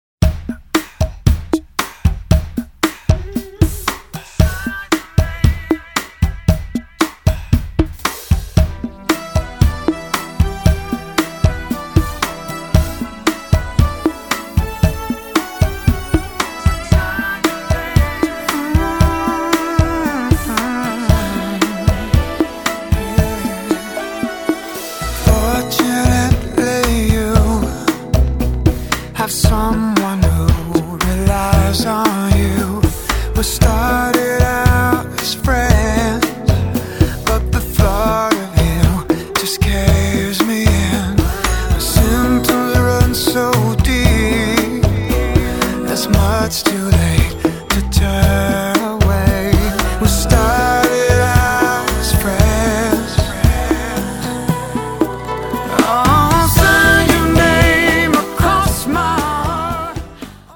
Sample tracks of this Exclusive  Mix CD: